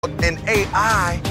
Play, download and share Common AI original sound button!!!!